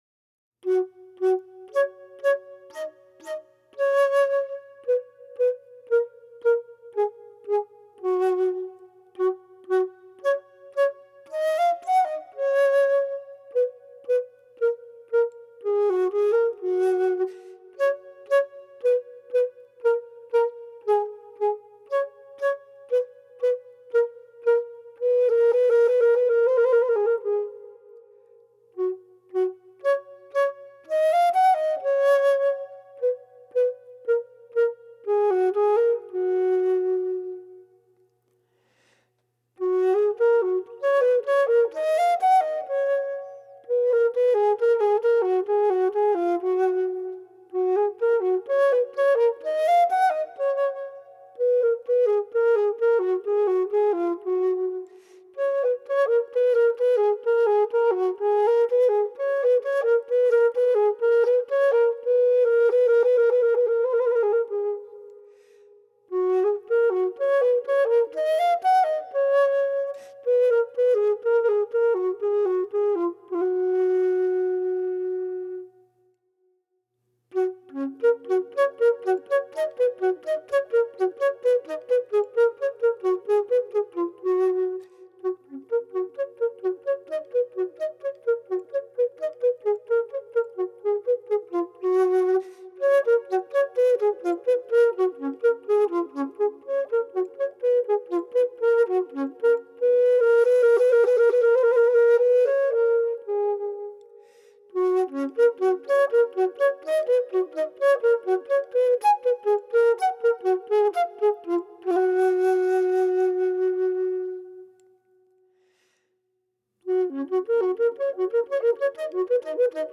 Les Vies Gigognes est un spectacle musical mêlant des compositions originales et des pièces baroques.
Air et variations pour flûte seule